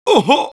Voice file from Team Fortress 2 Spanish version.
Heavy_painsharp03_es.wav